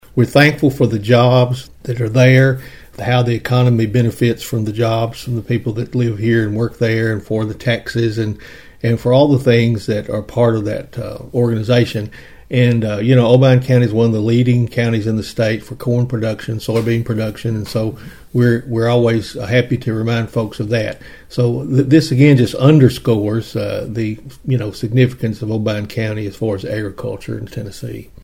Mayor Carr said Obion County’s reputation in corn production, and strong labor force, will help continue the success at the Obion facility.(AUDIO)